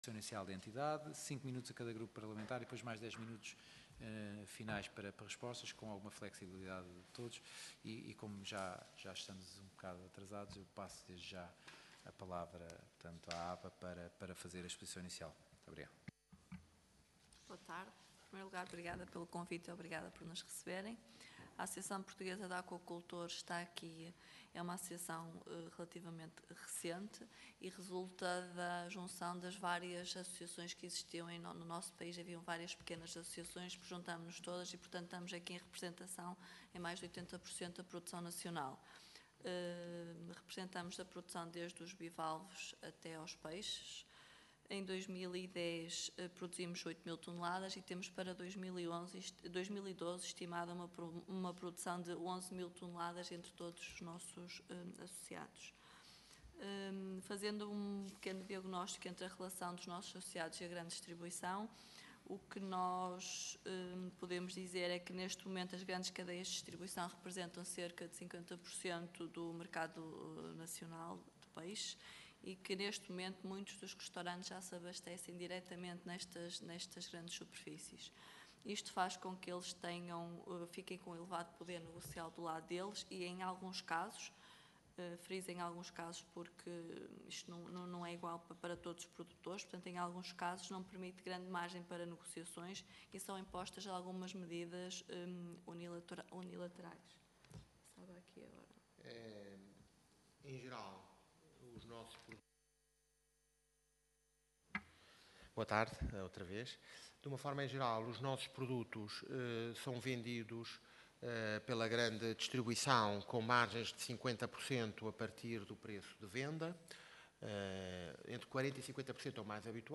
Grupo de Trabalho - Grande Distribuição e Produção Nacional Audição Parlamentar